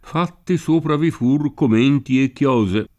commento [komm%nto] s. m. — antiq. comento [kom%nto]: Fatti sopra vi fur comenti e chiose [